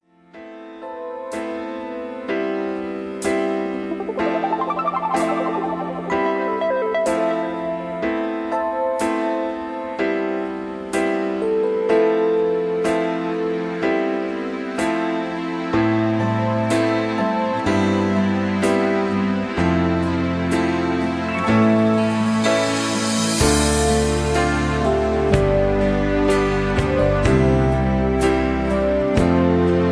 (Version-3, Key-Am) Karaoke MP3 Backing Tracks
Just Plain & Simply "GREAT MUSIC" (No Lyrics).